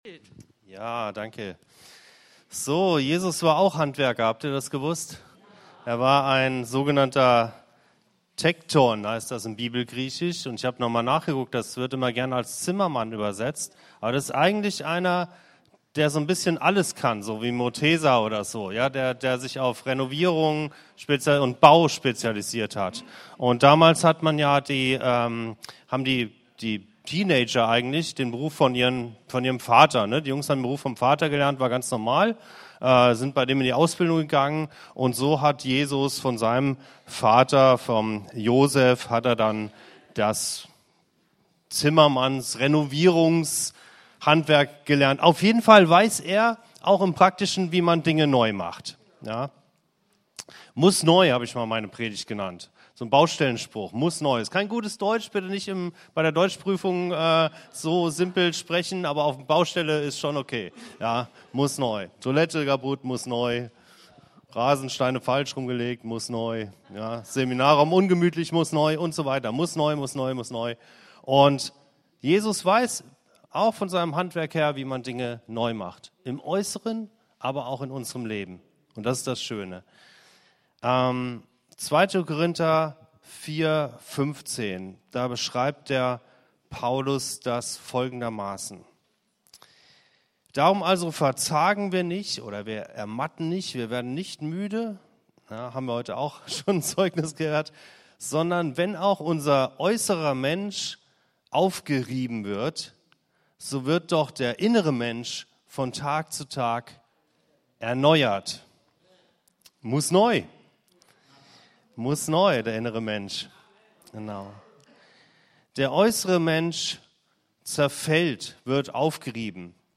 In dieser Predigt geht es um mehr als kaputte Toiletten und verlegte Rasensteine - es geht um unser Herz. So wie unser Gemeindezentrum renoviert wird, braucht auch unser Inneres Erneuerung: Denken, Wollen, Reaktionen, Charakter.